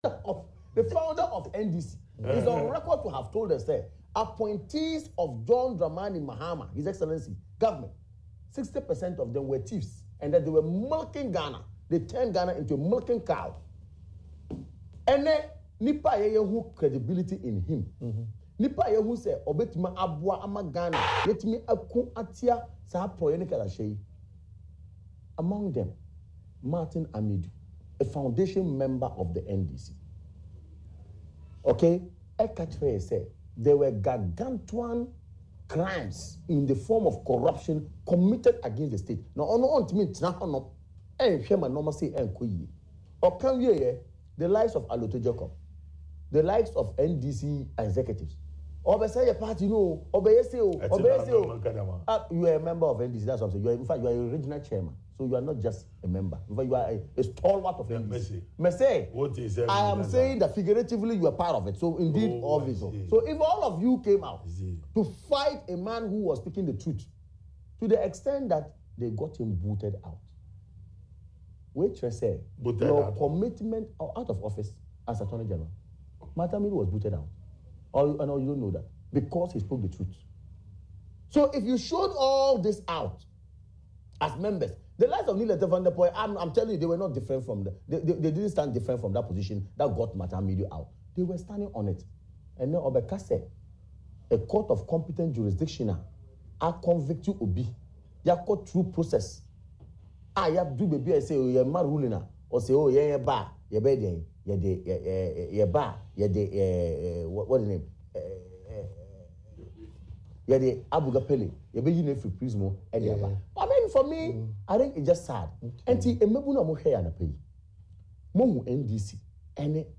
“They only came out with the statement just to cover up their criminal acts. Even their founder knows how corrupt they are and they have been corrupt over time and so it’s not surprising that they quickly come out with that fake release”, he said on Adom TV’s Morning Show, “Badwam” on Tuesday.